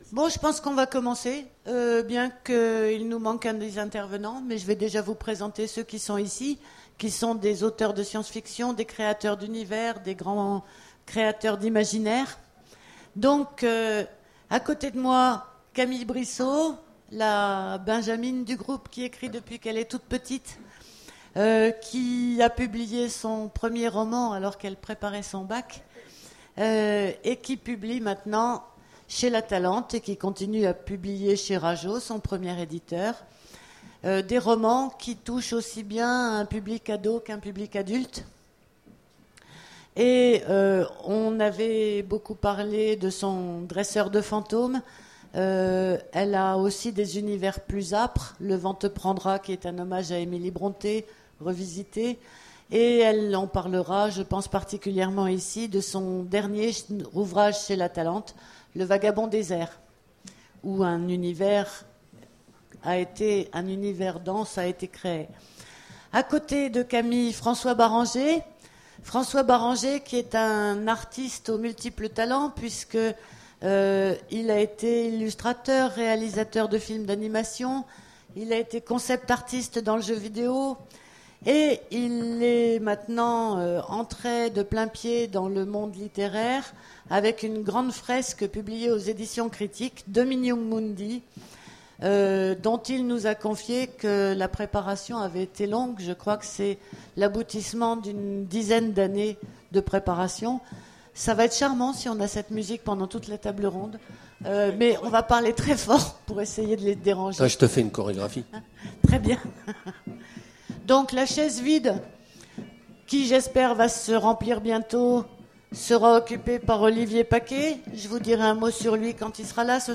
Imaginales 2015 : Conférence Auteurs de science-fiction...